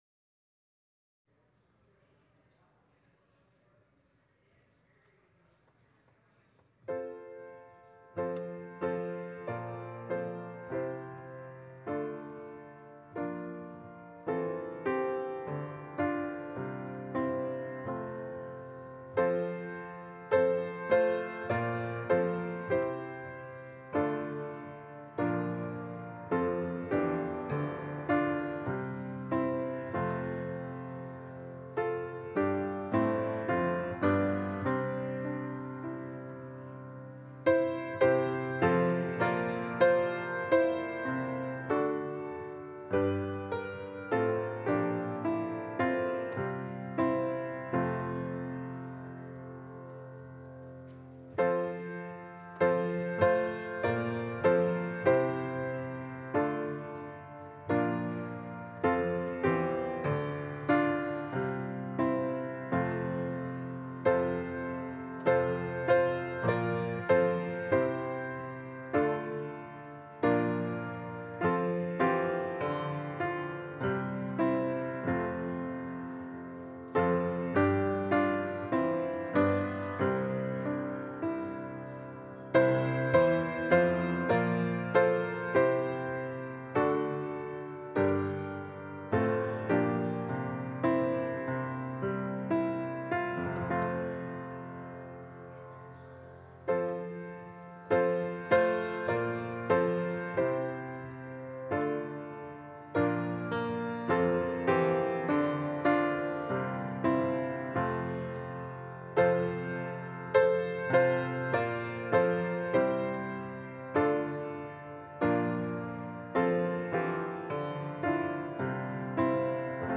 Passage: Matthew 3: 1-12 Service Type: Holy Day Service